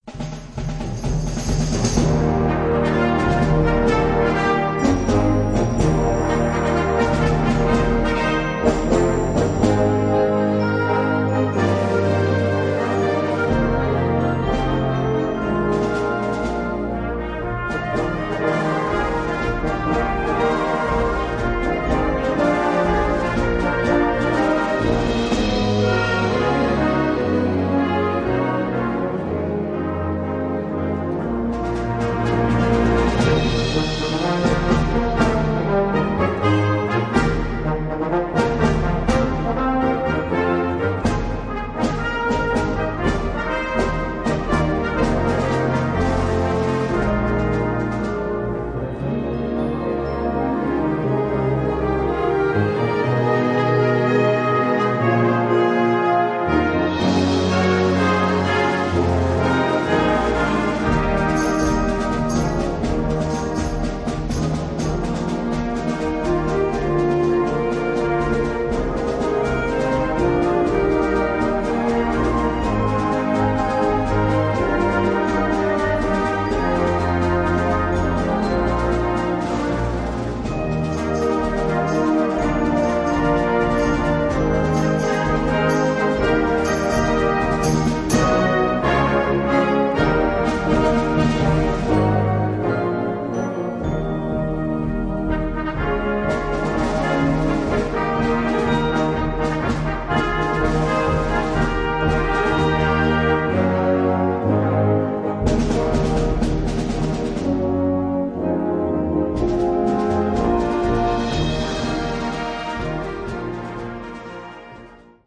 Gattung: Eröffnungsstück
Besetzung: Blasorchester